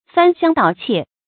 翻箱倒箧 fān xiāng dǎo qiè
翻箱倒箧发音
成语注音 ㄈㄢ ㄒㄧㄤ ㄉㄠˇ ㄑㄧㄝ ˋ